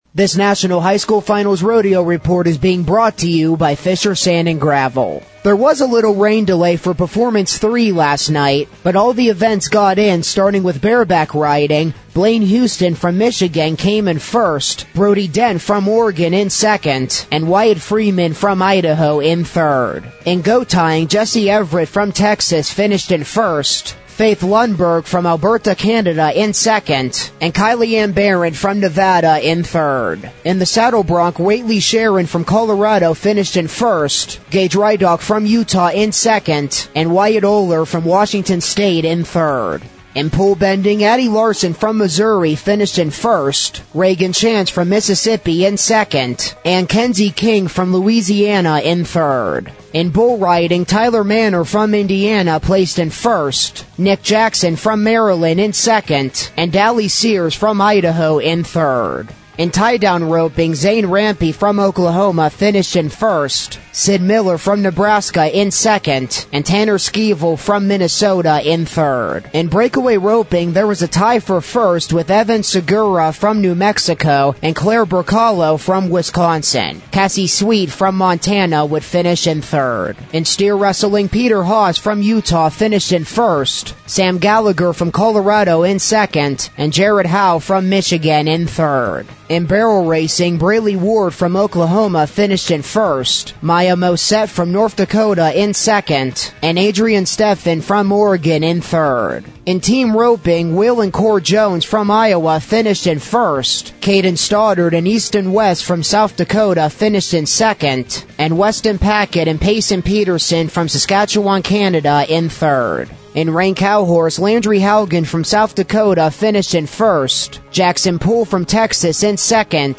This National High School Finals Rodeo Report is being brought to you by Fisher Sand & Gravel.